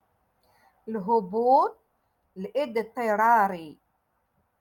Moroccan Dialect-Rotation Six- Lesson Nineteen